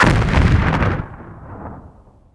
[WAV] Tank Audio
tank_sh_122.wav